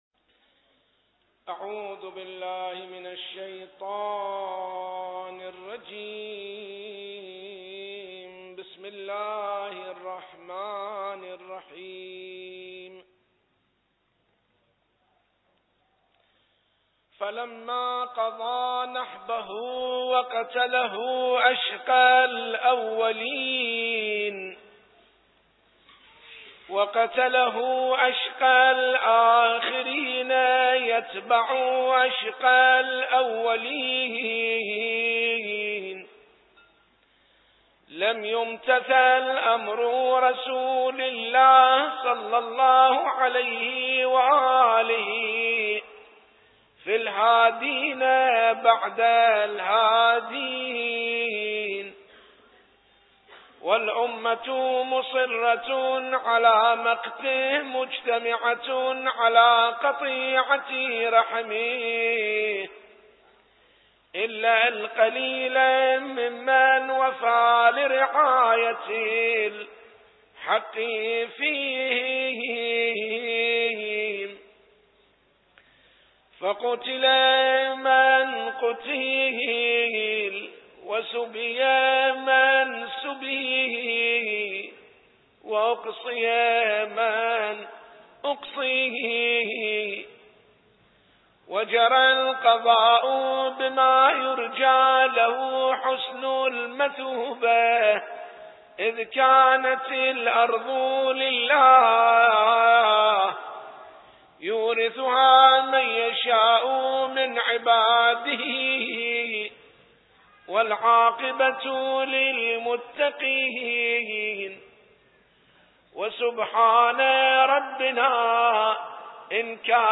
سلسلة: الحركة الإصلاحية من الإمام الحسين إلى الإمام المهدي (عليهما السلام) (9) المكان: الحسينية الفاطمية - النجف الأشرف التاريخ: 2006